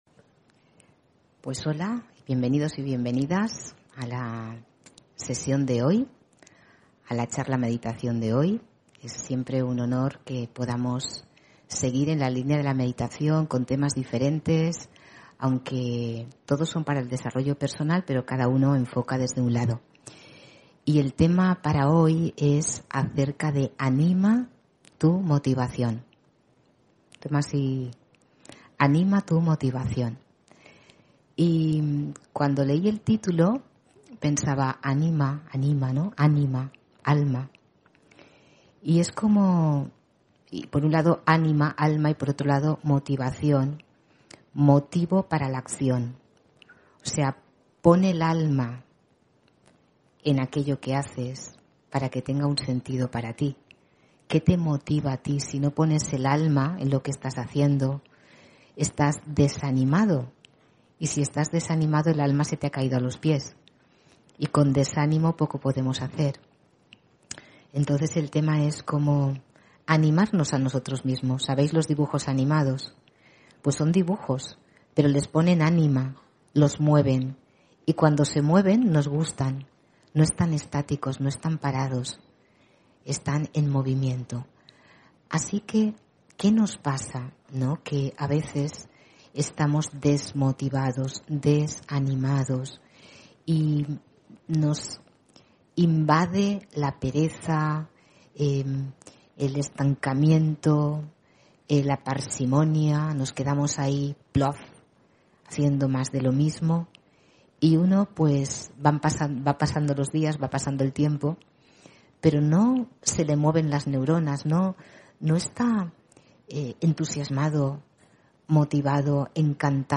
Meditación y conferencia: El poder de decidir (15 Noviembre 2022)